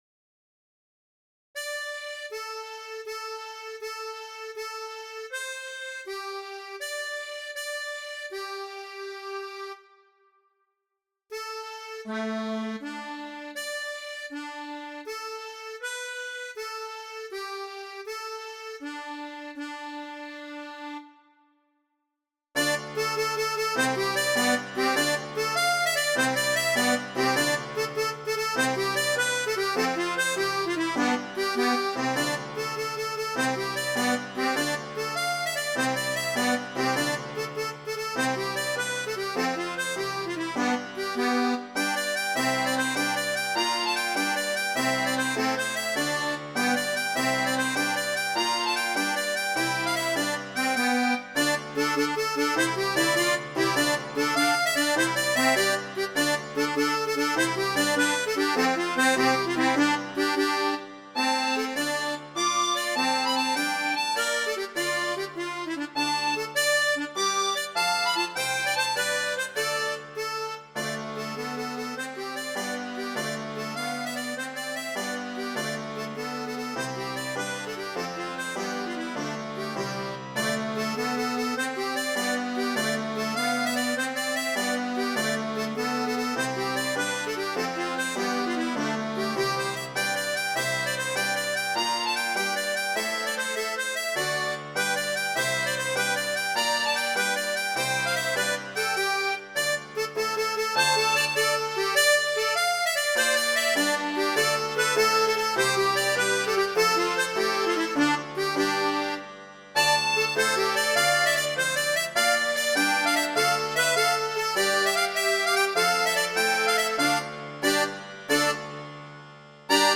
Trekzakduo (2025) / Strijkorkest (2025)